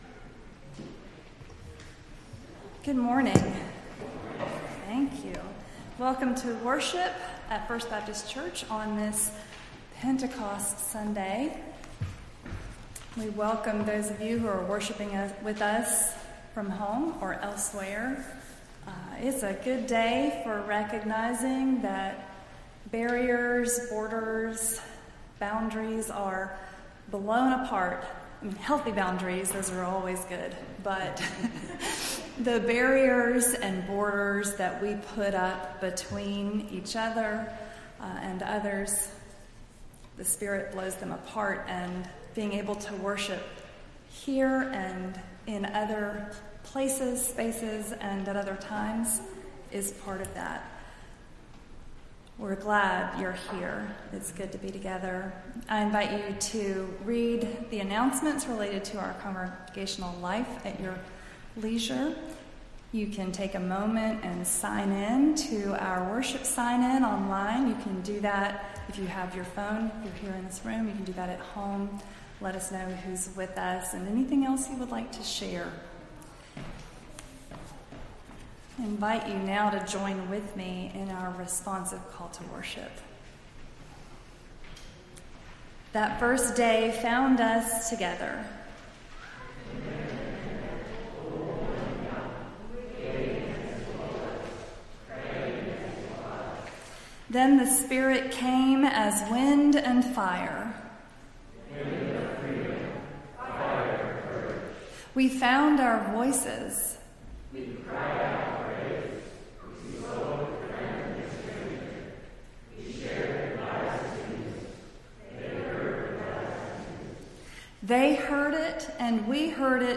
Entire June 5th Service